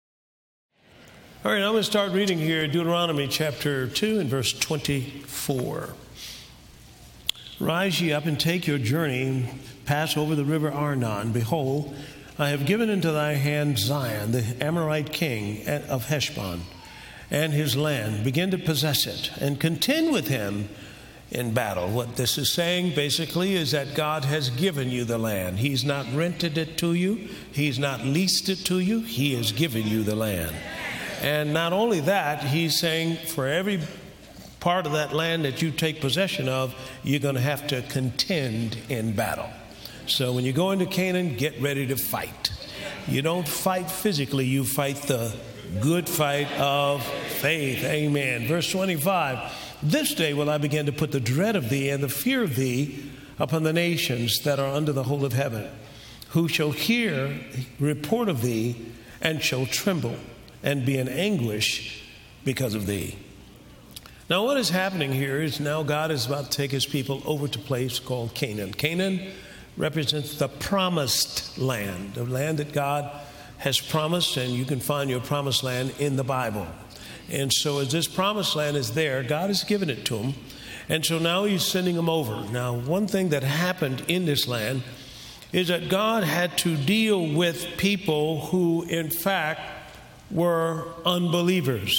(4 Teachings)